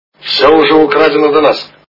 При прослушивании Операция Ы и другие приключения Шурика - Вы не скажете где здесь туалет качество понижено и присутствуют гудки.